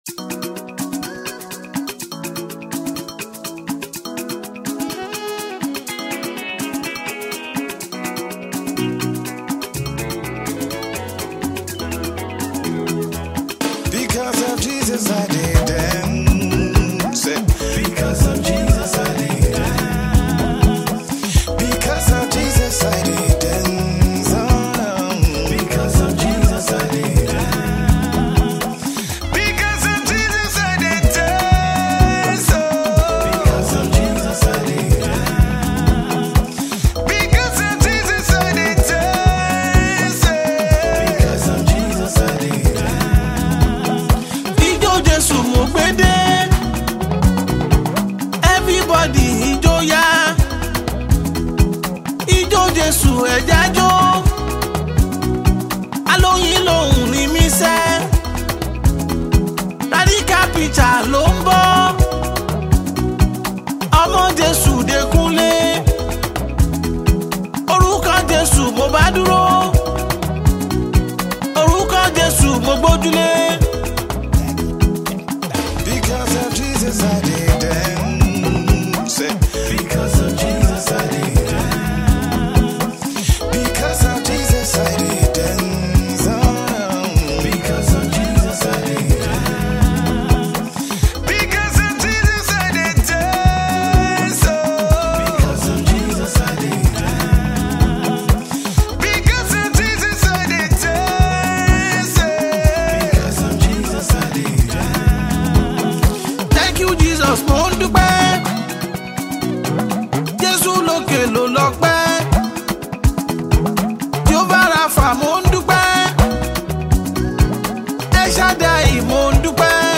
a heart felt praise song unto God